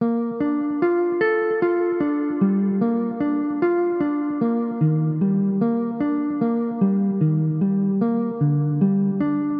爵士乐吉他循环播放经典原声
Tag: 100 bpm Hip Hop Loops Guitar Acoustic Loops 1.62 MB wav Key : Unknown FL Studio